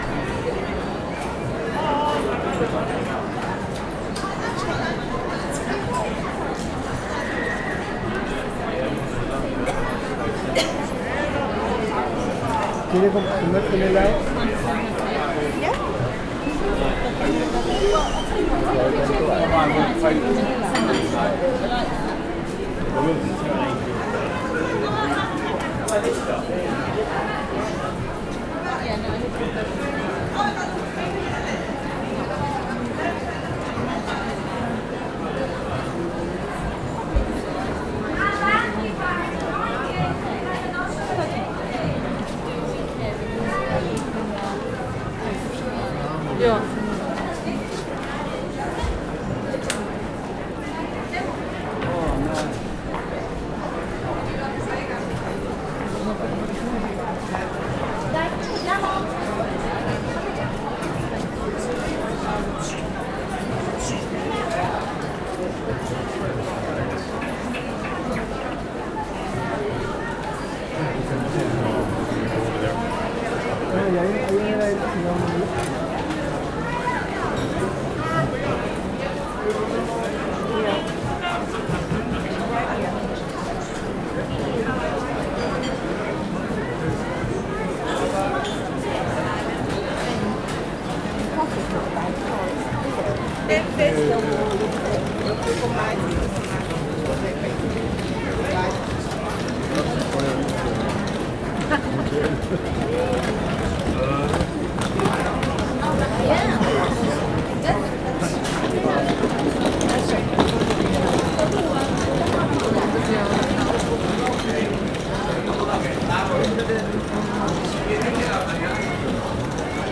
arkadalatt_fagyizomellett_szentmarkter_sds05.47.wav